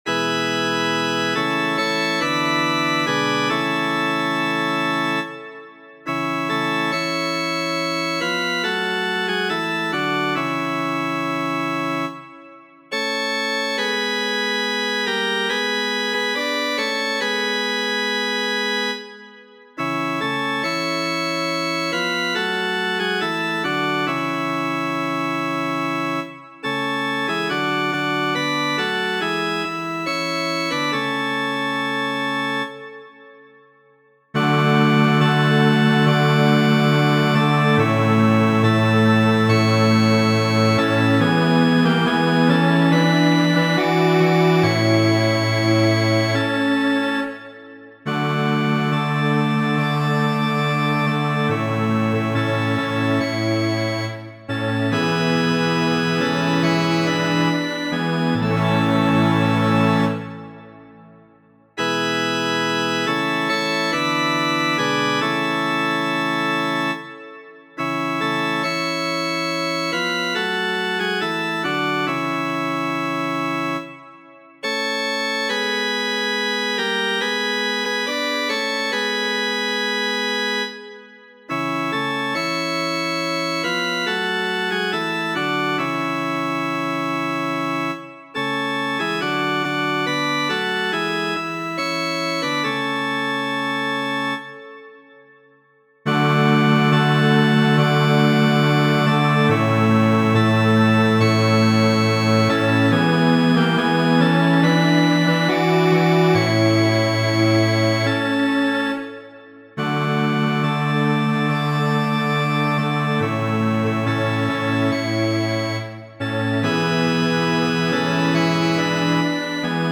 Ree ĉe ni, havaja kanto orĥestrigita de Dimitar Terziev.